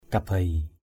/ka-beɪ/ (d.) trâu rừng, trâu min.